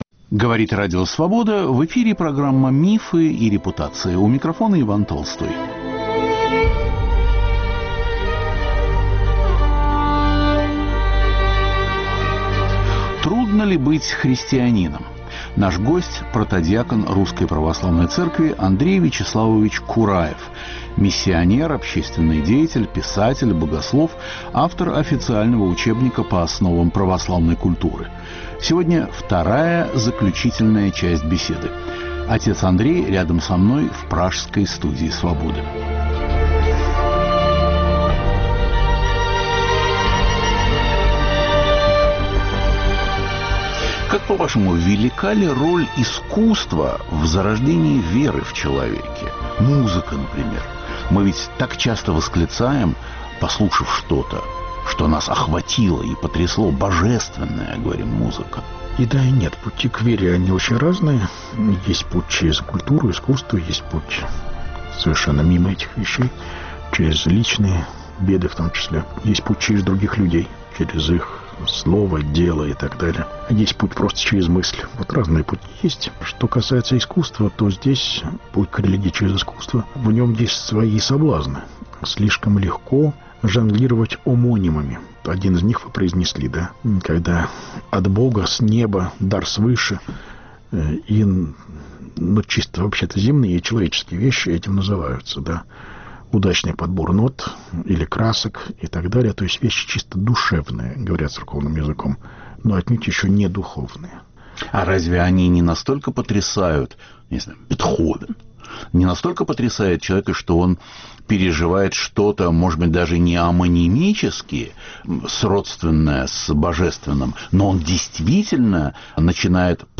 Заключительная беседа с протодиаконом Андреем Кураевым.